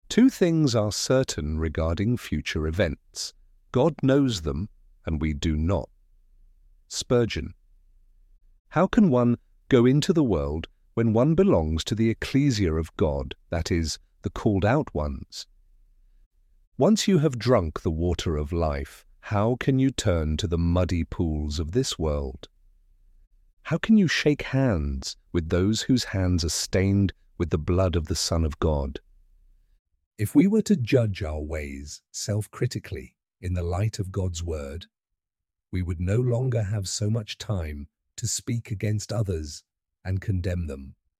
ElevenLabs_lines-and-lessons-5.mp3